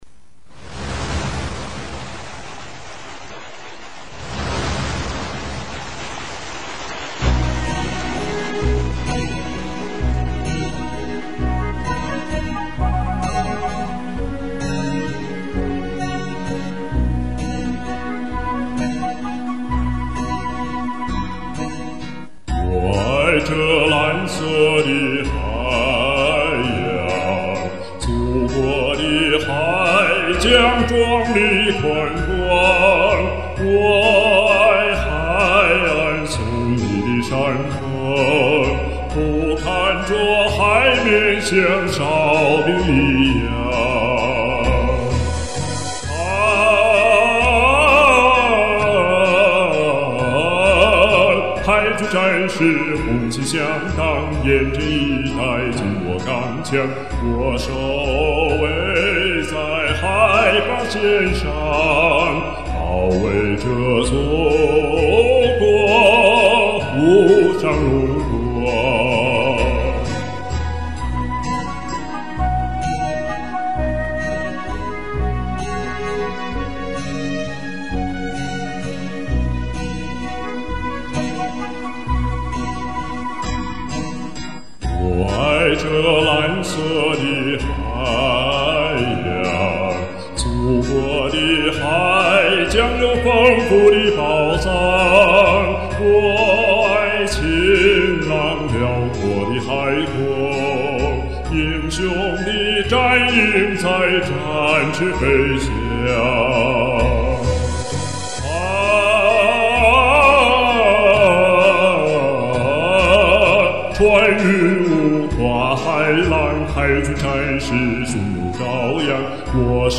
这歌熟透了，但不好唱，喘不上气来。